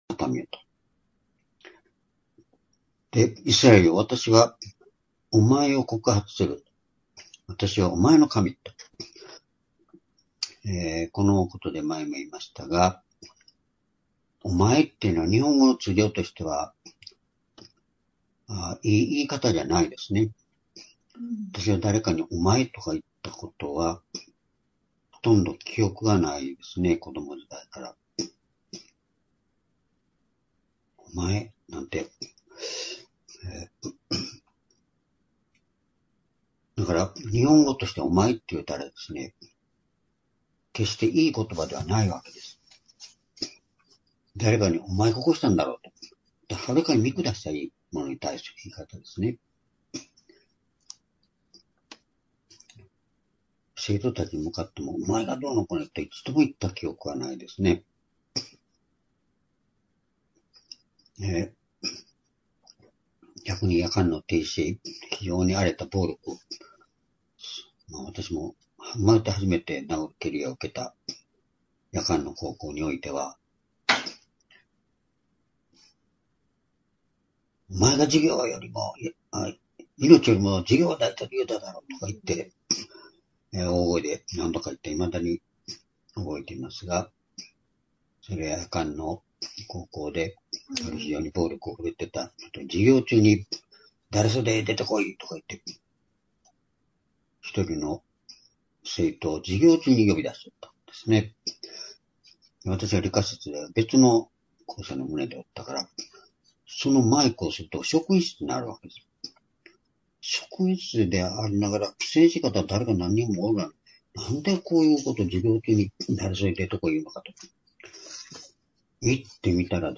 （主日・夕拝）礼拝日時 ２０２５年6月3日（夕拝） 聖書講話箇所 「世界はすべて神のもの」 詩編50の7-12 ※視聴できない場合は をクリックしてください。